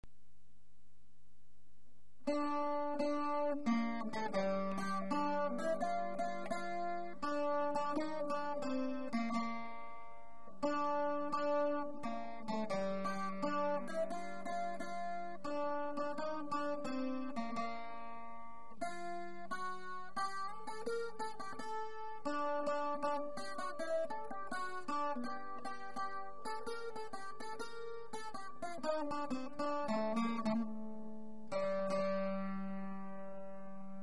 Musique classique